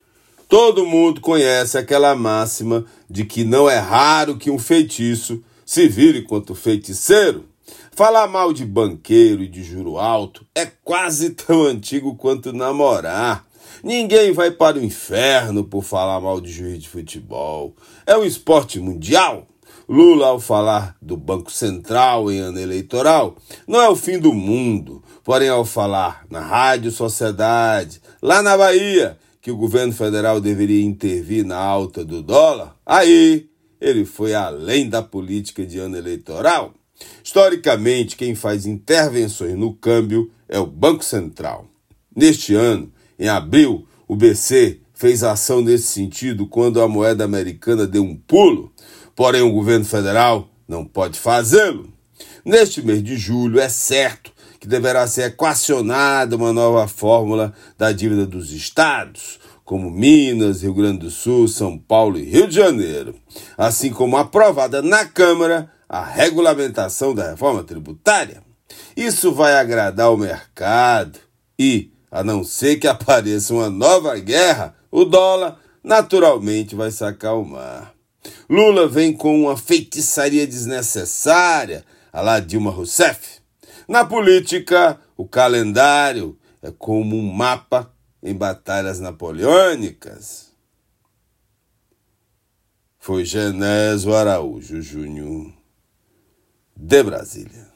comentario-03-07.mp3